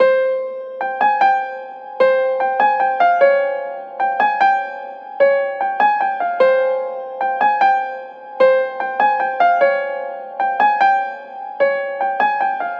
描述：Piano/Keys Loop 调：F小调 BPM：150
Tag: 150 bpm Trap Loops Piano Loops 2.15 MB wav Key : F